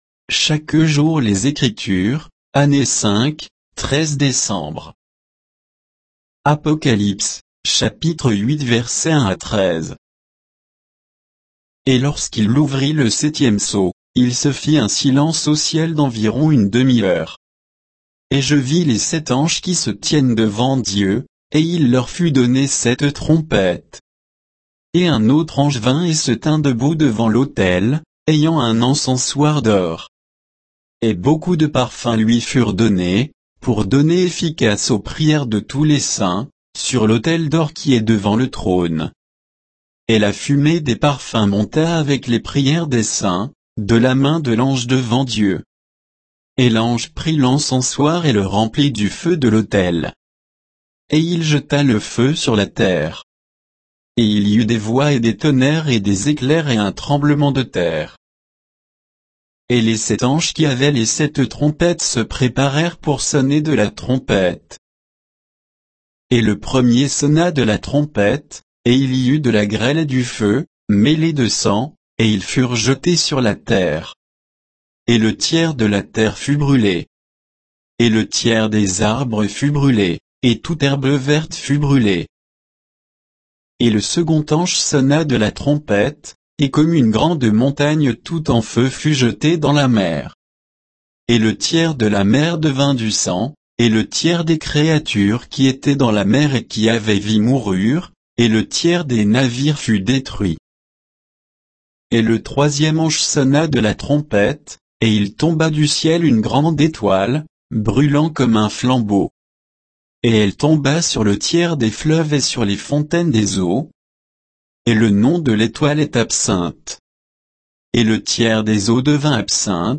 Méditation quoditienne de Chaque jour les Écritures sur Apocalypse 8, 1 à 13